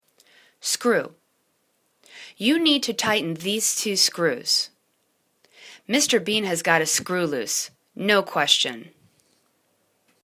screw    /skru:/    n